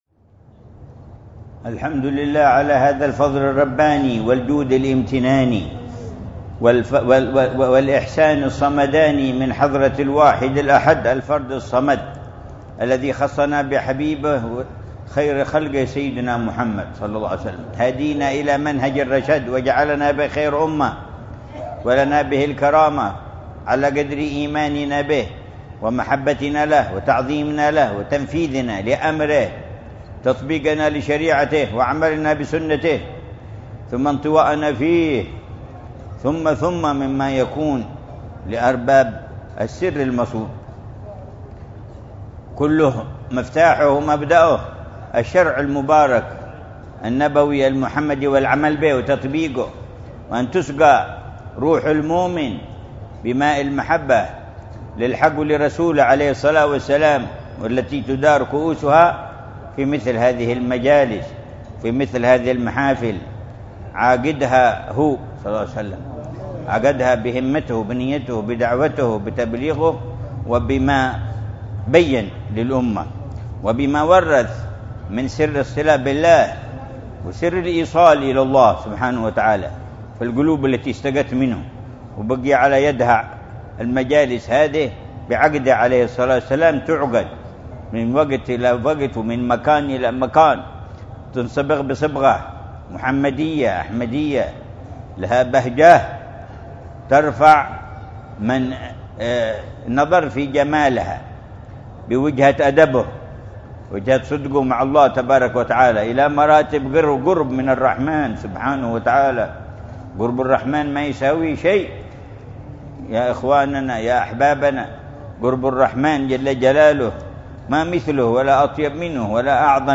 مذاكرة العلامة الحبيب عمر بن محمد بن حفيظ في مسجد العناية، بحارة عيديد القبلية، تريم، ليلة الثلاثاء 9 ربيع الثاني 1445هـ بعنوان: